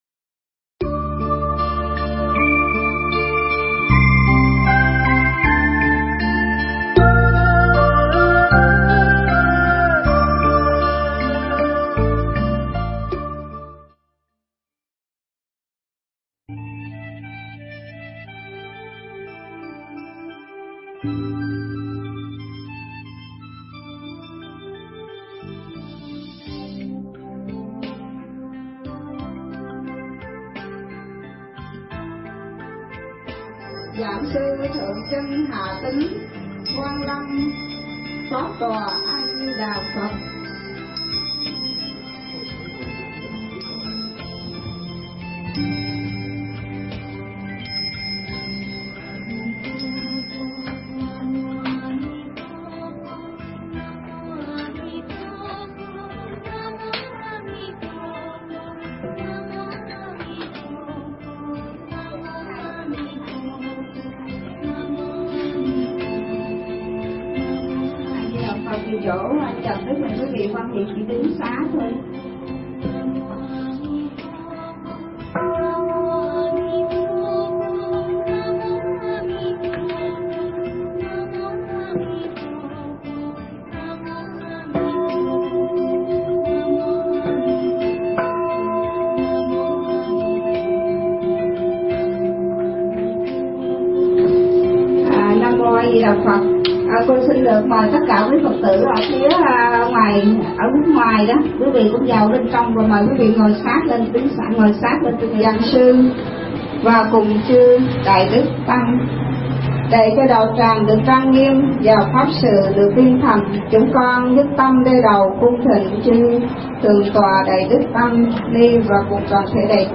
Nghe Mp3 thuyết pháp Nhân Duyên
Mp3 pháp thoại Nhân Duyên